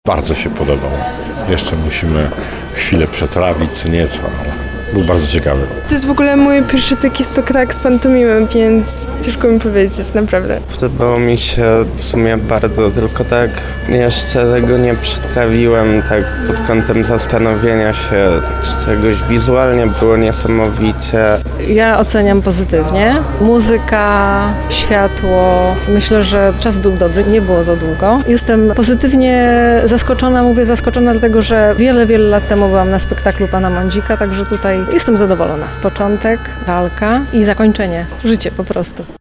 Nasza reporterka zapytała widzów o ich przemyślenia po obejrzeniu sztuki.